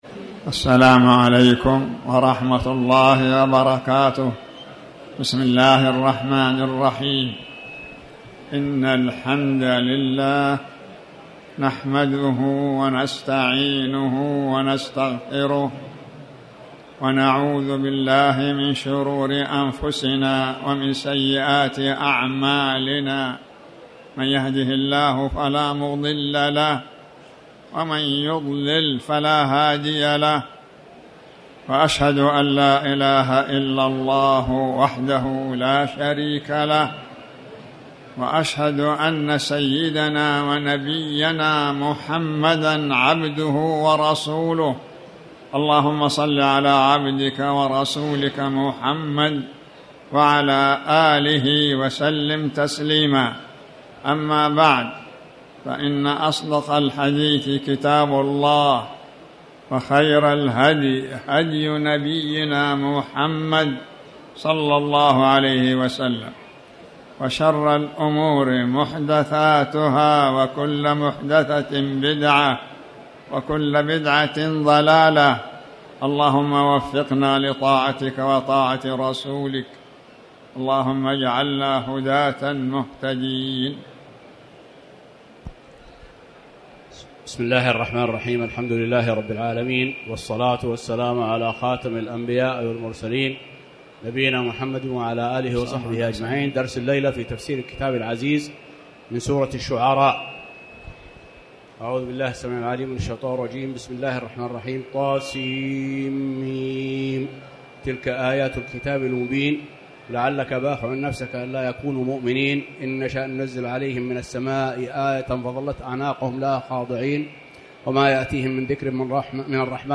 تاريخ النشر ٥ ذو القعدة ١٤٤٠ هـ المكان: المسجد الحرام الشيخ